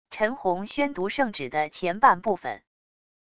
陈洪宣读圣旨的前半部分wav音频